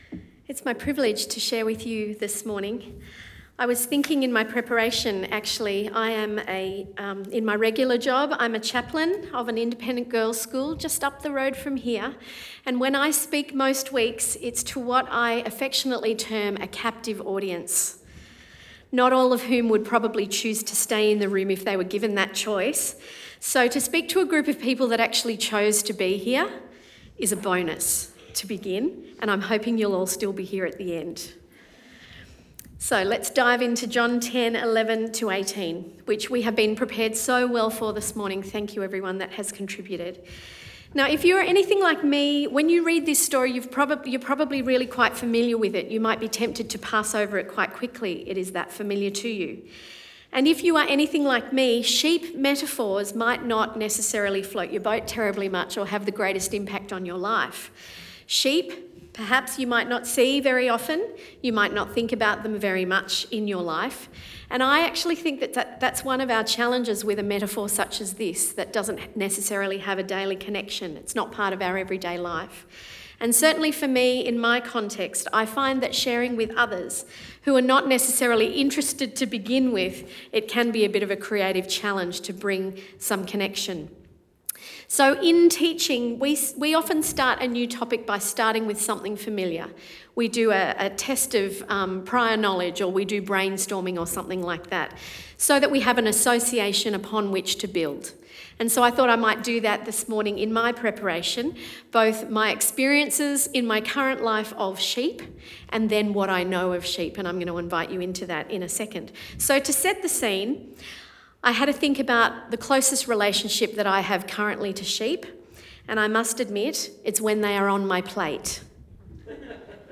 Sermon Podcasts I AM